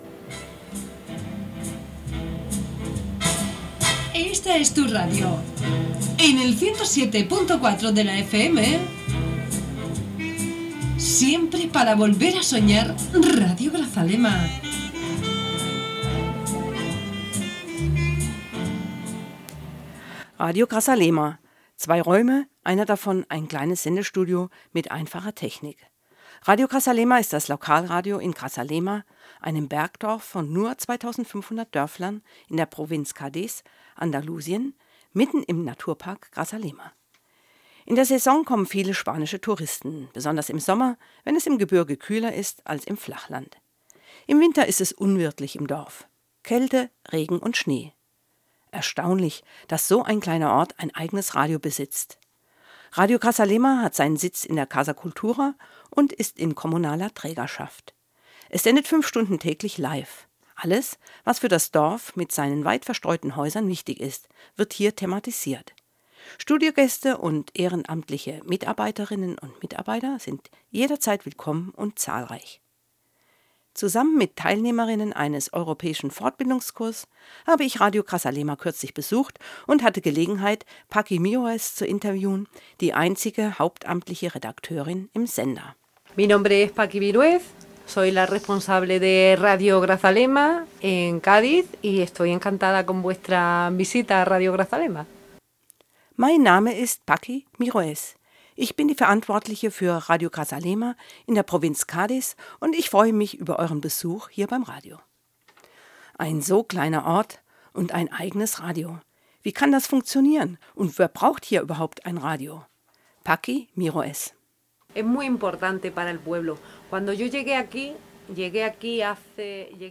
Gebauter Beitrag